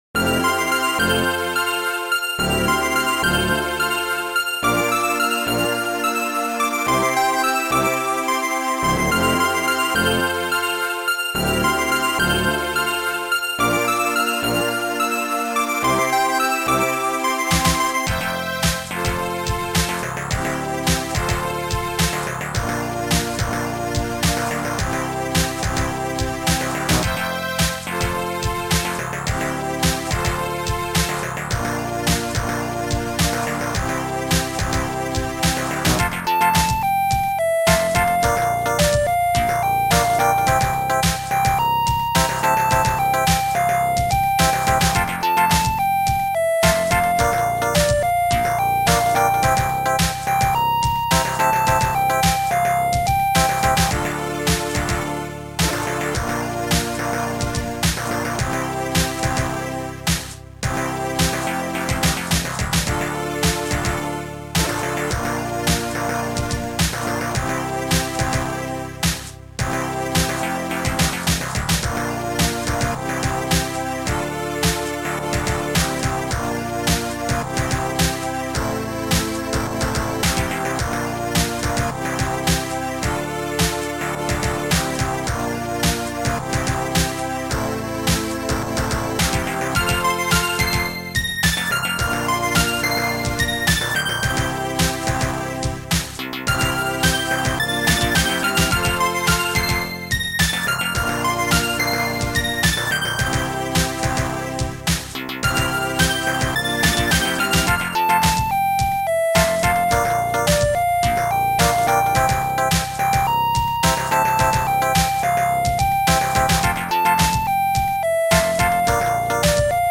Sound Format: Startrekker
Sound Style: Disco Pop / Melody